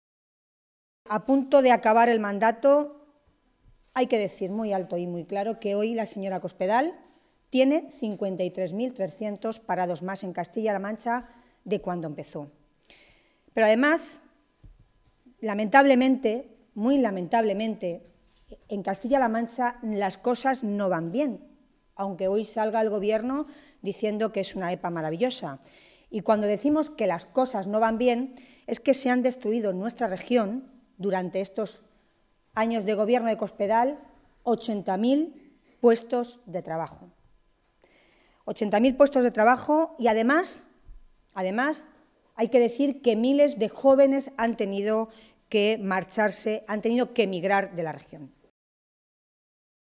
Tolón se pronunciaba de esta manera esta mañana, en Toledo, en una comparecencia ante los medios de comunicación en la que valoraba esa EPA del último trimestre del año 2014.
Cortes de audio de la rueda de prensa